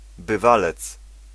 Ääntäminen
Ääntäminen France Tuntematon aksentti: IPA: /a.bi.tɥe/ Haettu sana löytyi näillä lähdekielillä: ranska Käännös Ääninäyte 1. bywalec {m} Suku: m .